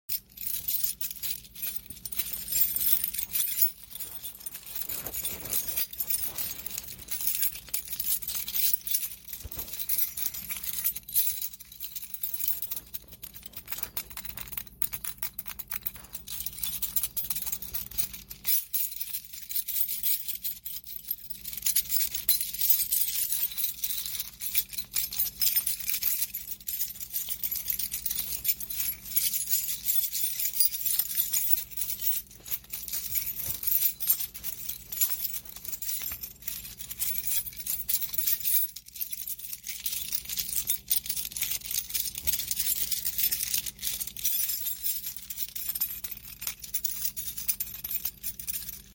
ASMR Shaking keys and sound effects free download
ASMR - Shaking keys and playing with them